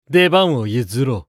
厨二病ボイス～戦闘ボイス～
【交代ボイス(戻)1】